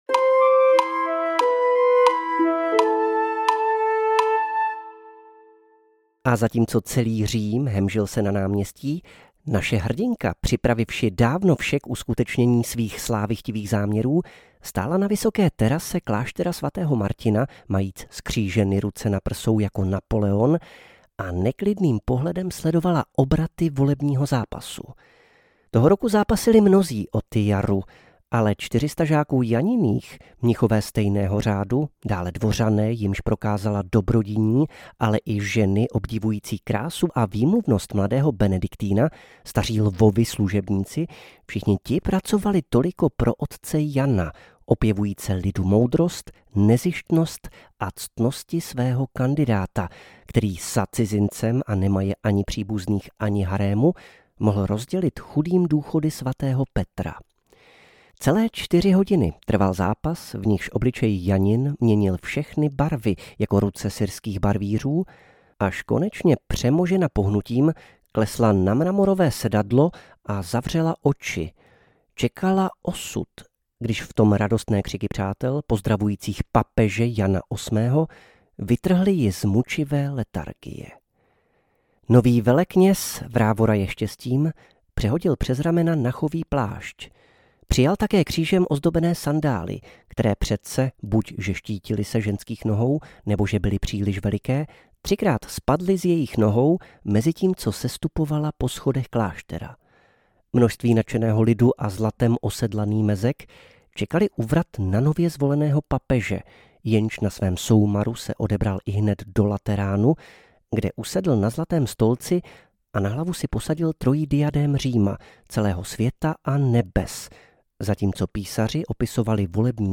Papežka Jana audiokniha
Ukázka z knihy
papezka-jana-audiokniha